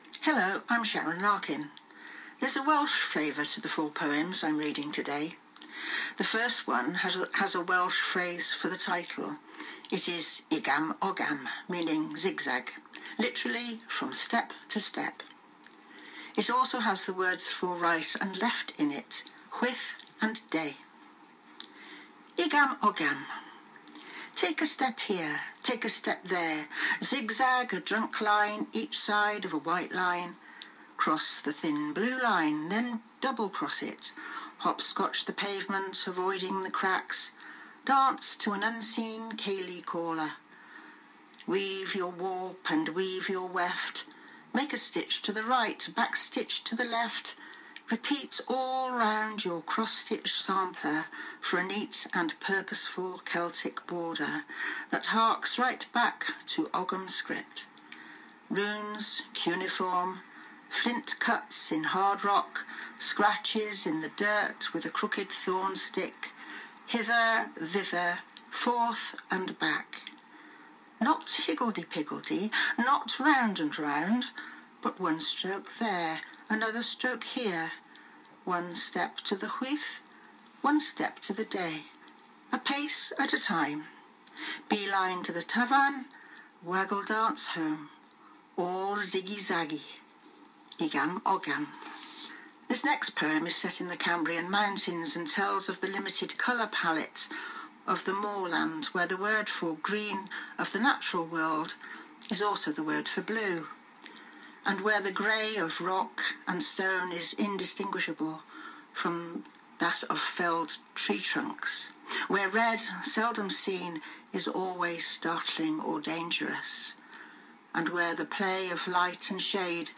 Poems, including Igam Ogam, Colours and Transhumance on ‘The Writer’s Room’, Corinium Radio, 1 December 2016